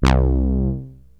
SYNTH BASS-1 0012.wav